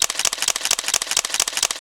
stich_shot.ogg